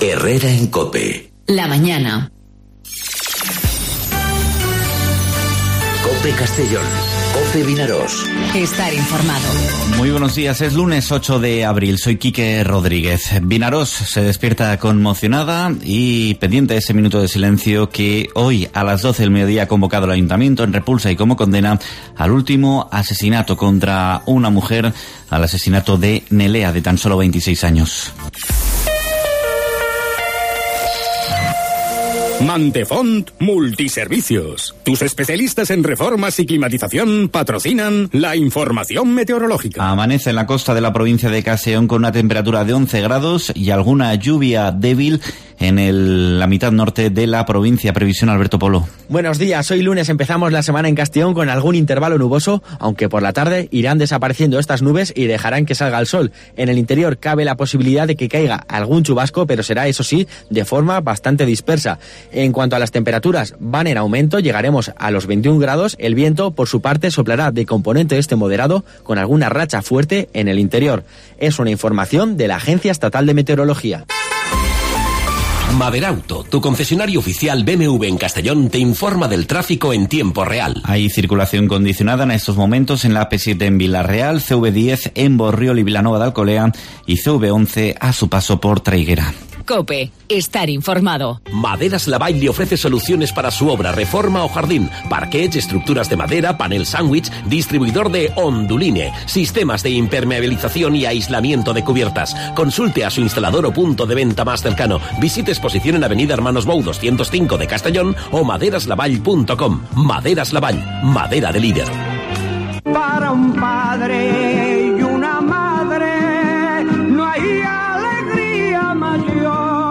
Informativo 'Herrera en COPE' Castellón (08/04/2019)